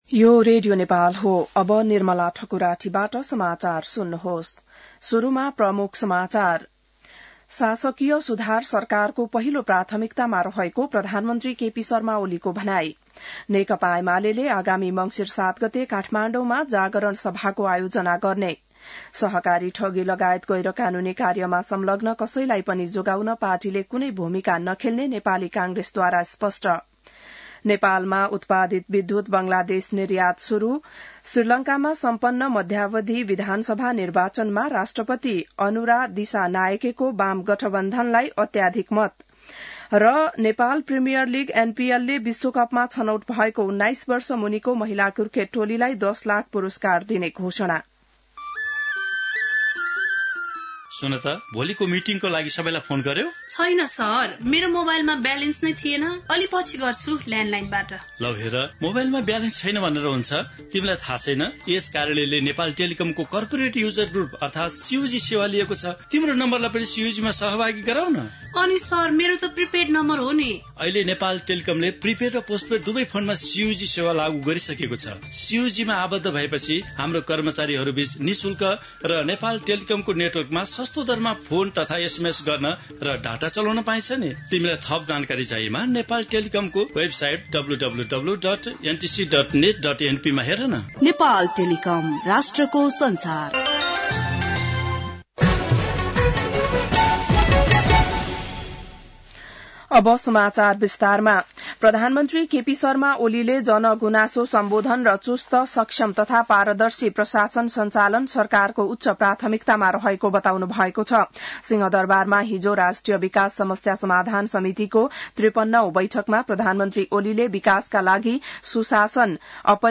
बिहान ७ बजेको नेपाली समाचार : २ मंसिर , २०८१